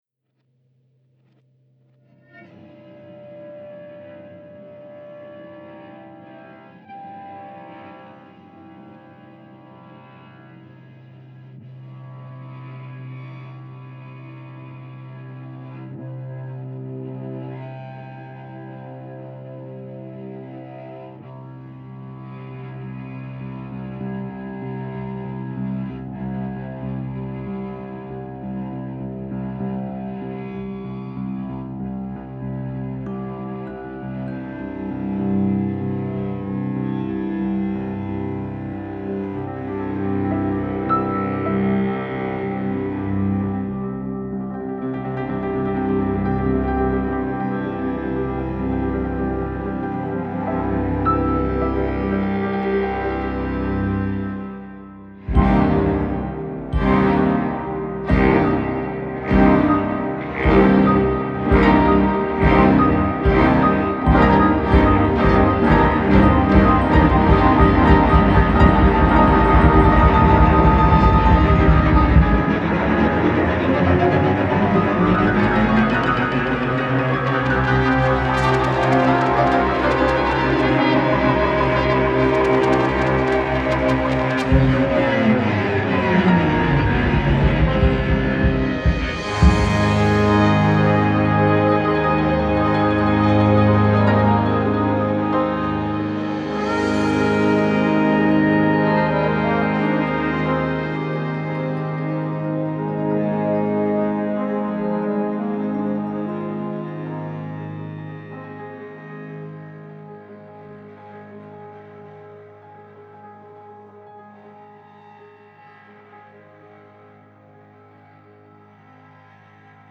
But to create a podcast wormhole, you just need a cello and french horn and a few hours with the house to yourself.